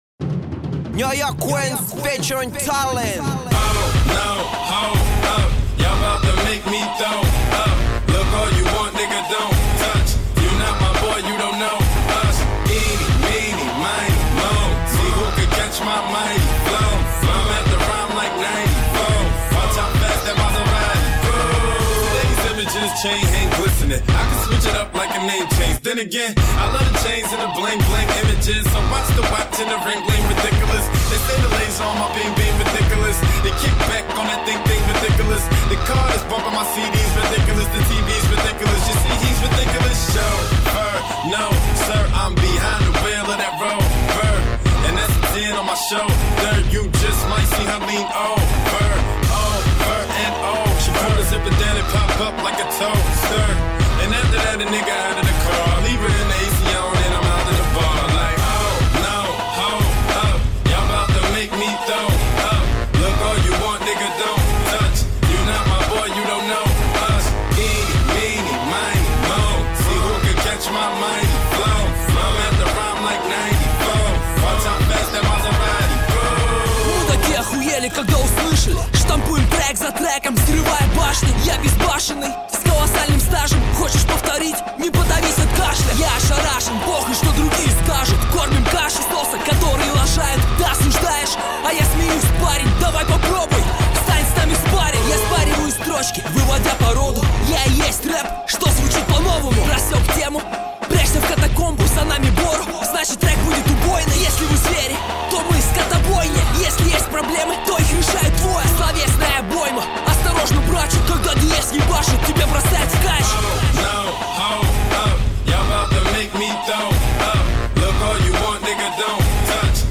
Дэмо музыка: Рэп Комментарии